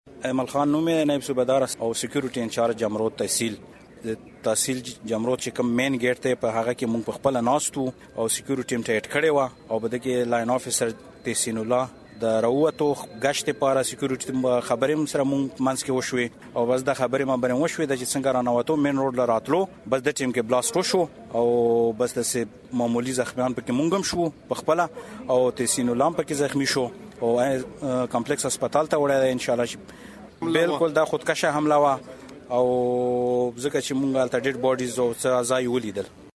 په جمرود کې ځانمرګي برید د سترګو لېدلې حال